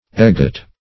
egghot - definition of egghot - synonyms, pronunciation, spelling from Free Dictionary
Search Result for " egghot" : The Collaborative International Dictionary of English v.0.48: Egghot \Egg"hot`\, n. A kind of posset made of eggs, brandy, sugar, and ale.